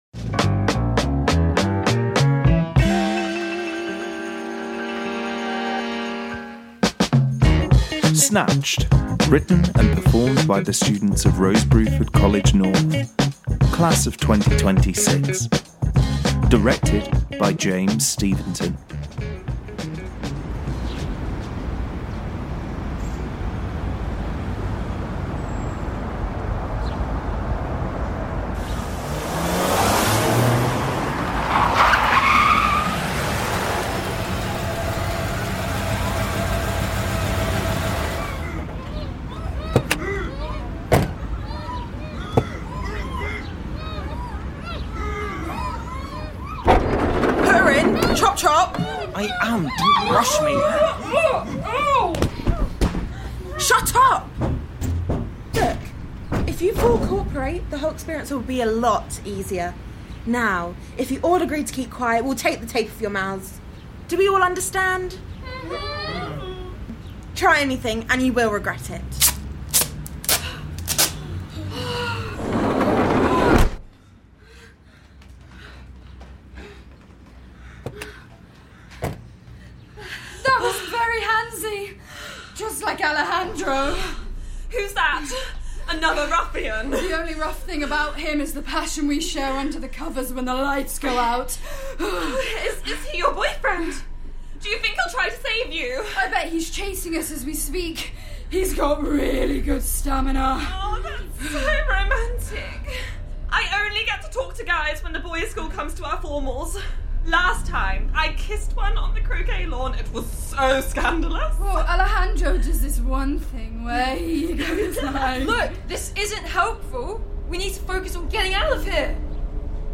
Recorded at: White Bear Studios, Manchester